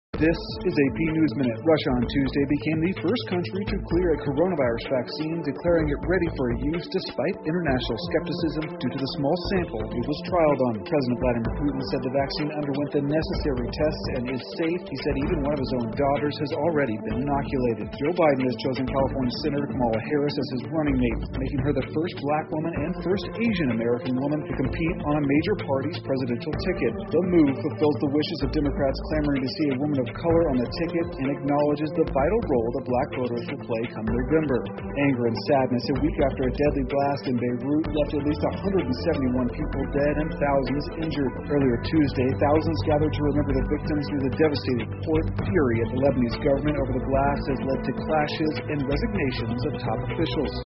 美联社新闻一分钟 AP 俄罗斯注册世界首款新冠疫苗 听力文件下载—在线英语听力室